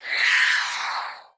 sounds / mob / vex / death2.ogg
death2.ogg